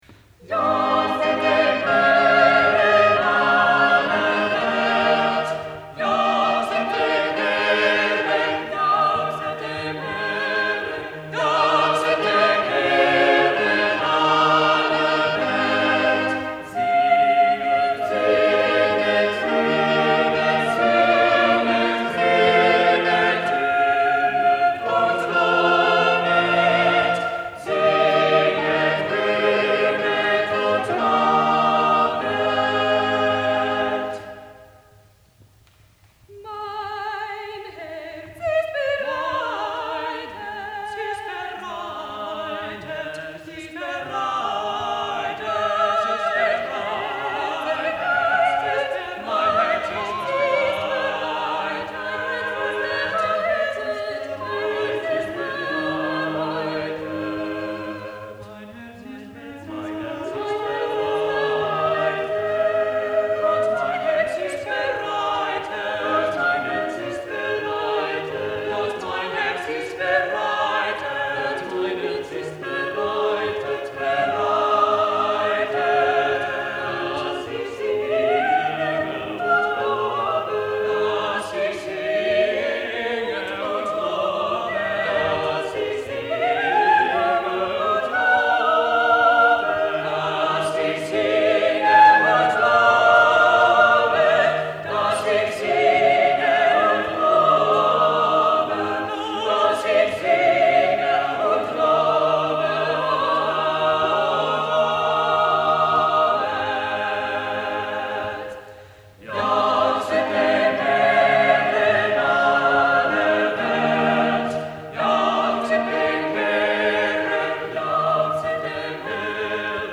This fine chorale concertato is found in the Clausholm fragments–manuscript sheets used to repair the bellows of the 18th century organ of Clausholm Castle in Denmark.
| Vocal and Instrumental Ensemble 'Northern Forests' 1980